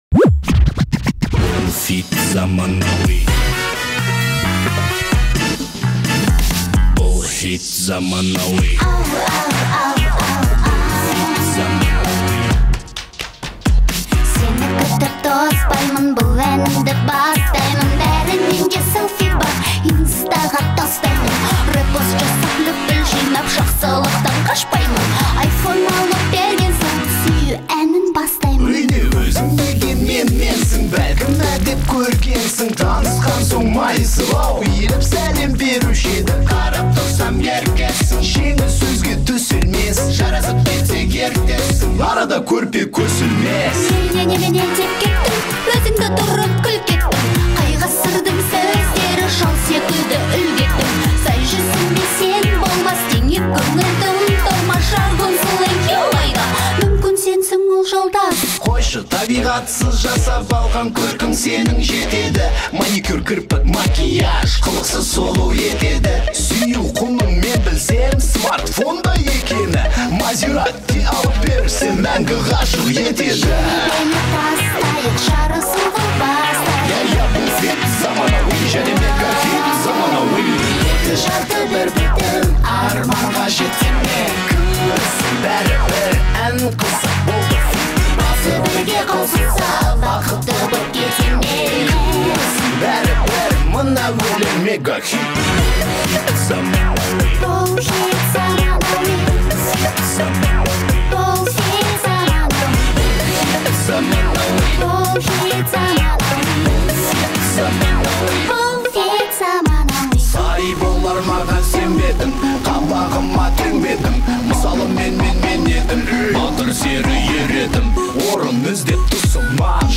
современный казахский трек